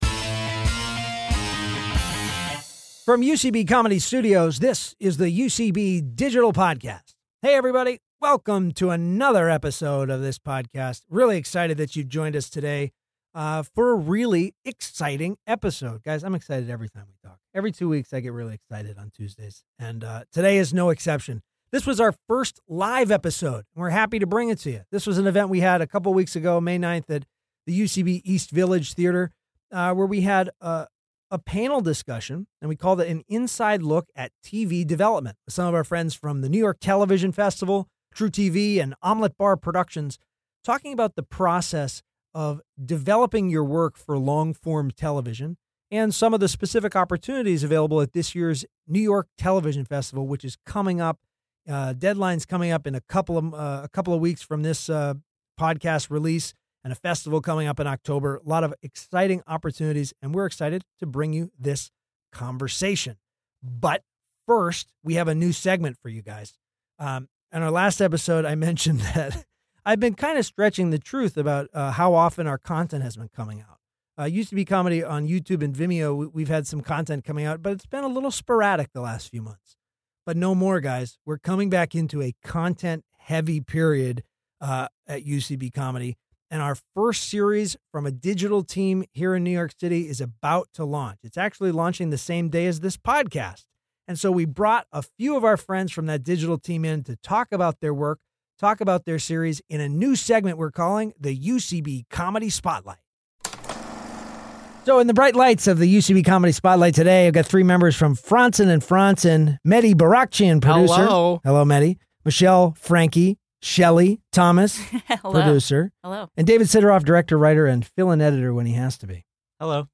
The New York Television Festival and UCB Comedy together present a live panel discussion about the process of developing your work for long form television and some of the opportunities at this year’s festival for creators to submit.
Recorded live at UCBT East Village and at UCB Comedy Studios East in New York City.